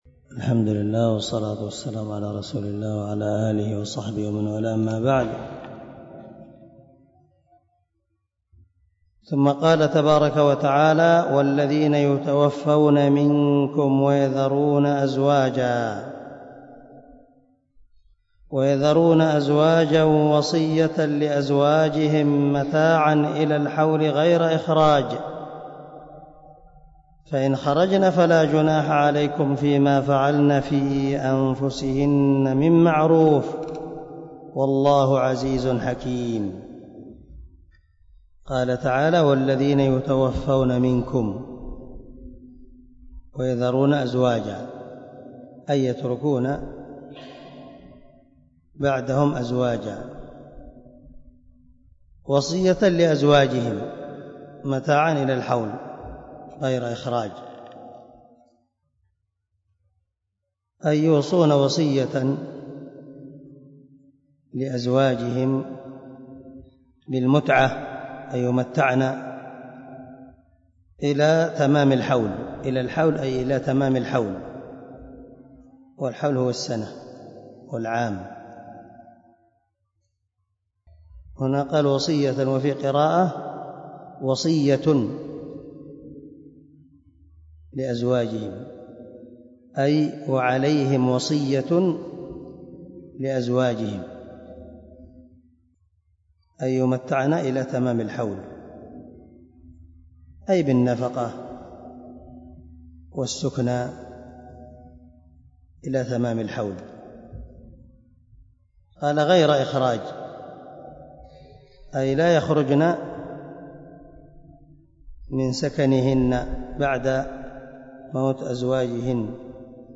126الدرس 116 تفسير آية ( 240 - 242 ) من سورة البقرة من تفسير القران الكريم مع قراءة لتفسير السعدي
دار الحديث- المَحاوِلة- ا